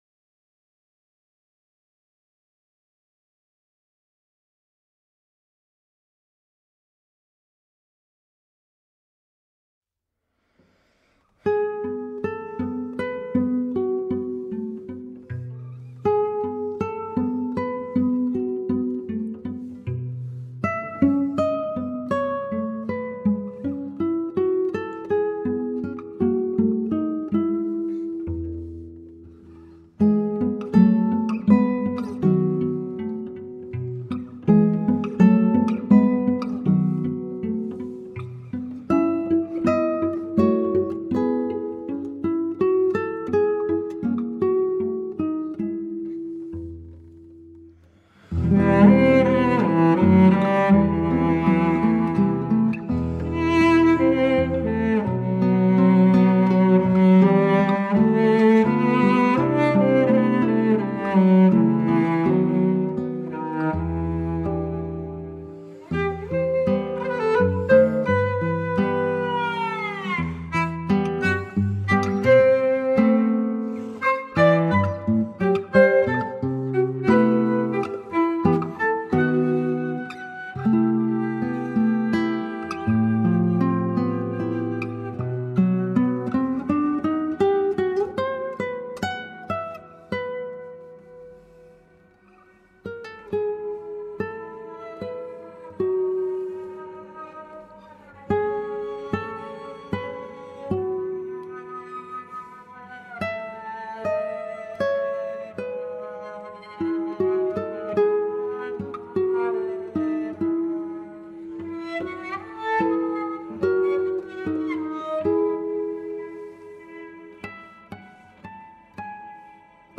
violonchelo
guitarra